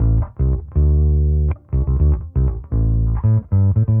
Index of /musicradar/dusty-funk-samples/Bass/120bpm
DF_PegBass_120-A.wav